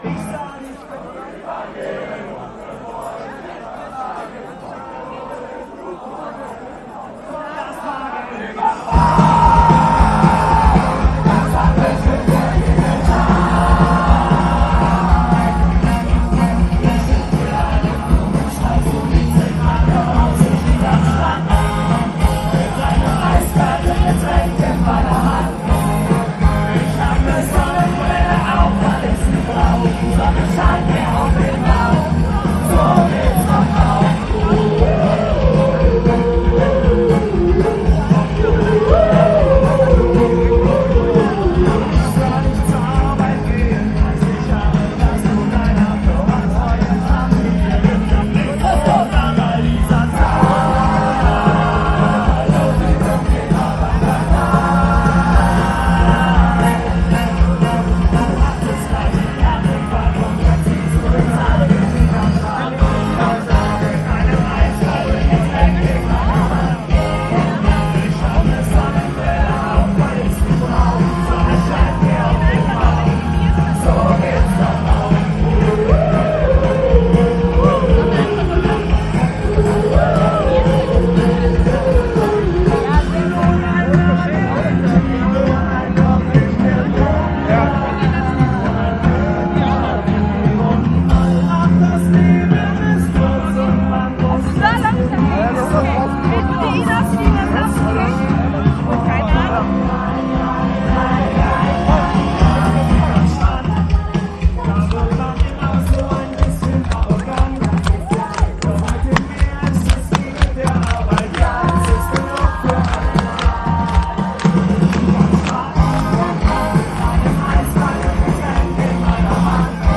Aufnahmegerät: Sharp IM-DR420H (LP4-Modus)
Mikrofon: Sony ECM-T6 (Mono)